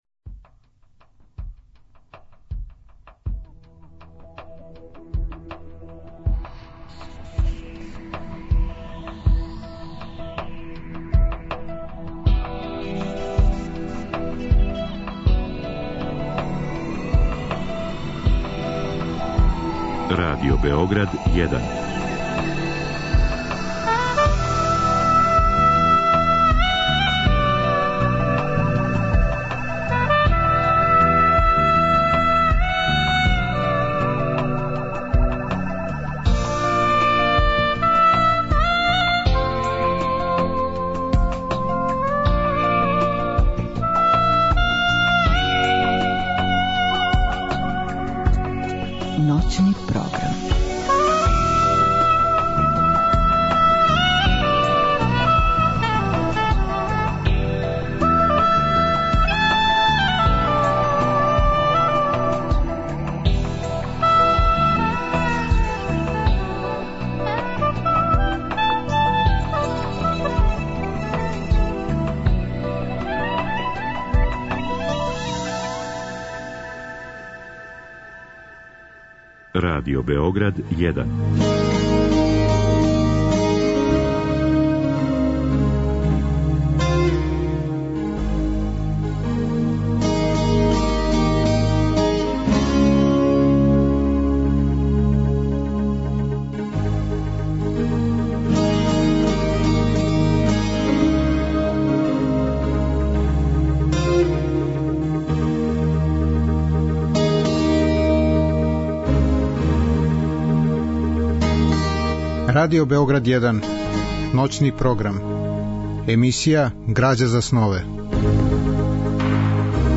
Разговор и добра музика требало би да кроз ову емисију и сами постану грађа за снове.
Радио-драма је реализована 1982. године у продукцији Драмског програма Радио Београда.